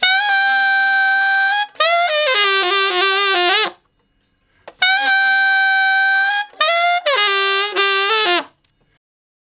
066 Ten Sax Straight (D) 27.wav